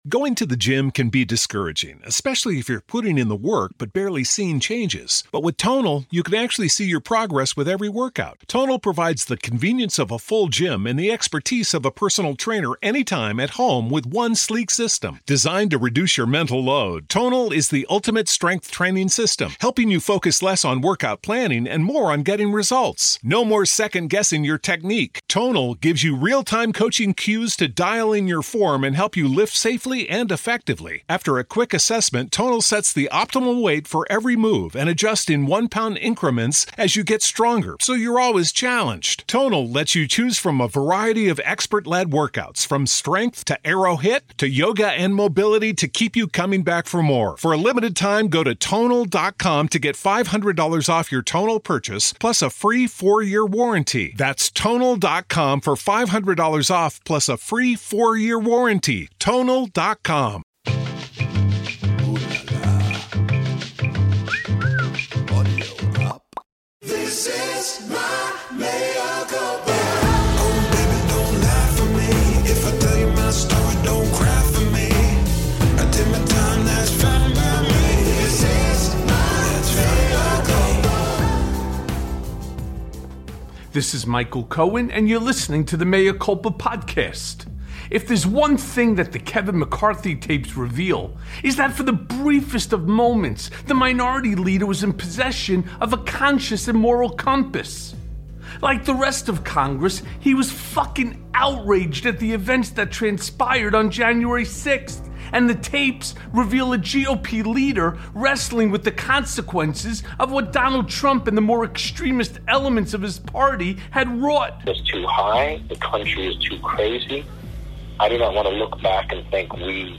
Lincoln Project advisor Stuart Stevens joins Mea Culpa to say where the GOP bodies are really buried.